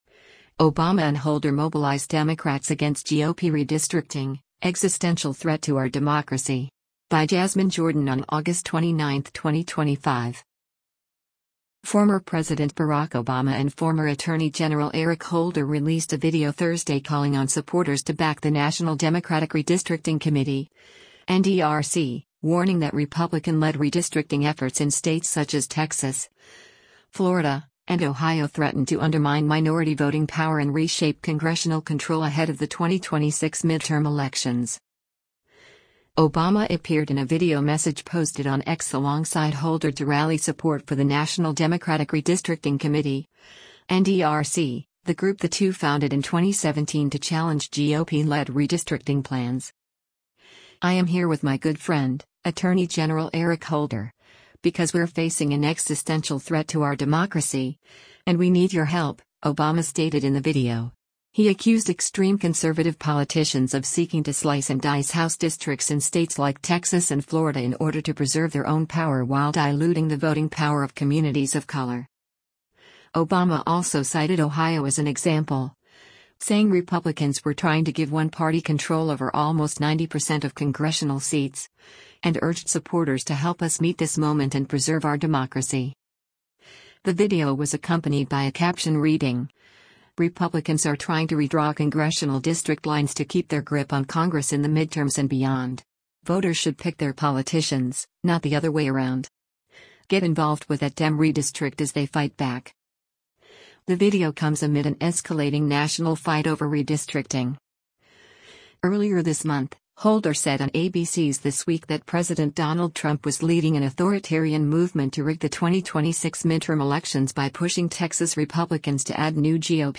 Obama appeared in a video message posted on X alongside Holder to rally support for the National Democratic Redistricting Committee (NDRC), the group the two founded in 2017 to challenge GOP-led redistricting plans.